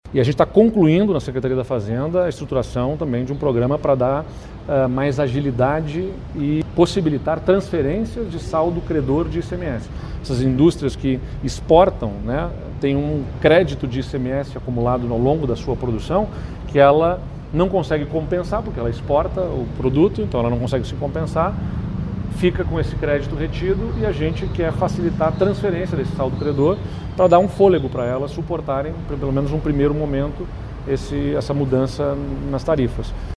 O governador do Rio Grande do Sul ainda disse que o Executivo gaúcho vai apresentar medidas próprias para também auxiliar empresas afetadas pelo tarifaço americano. (Abaixo, manifestação de Eduardo Leite).
02.-SONORA-EDUARDO-LEITE2.mp3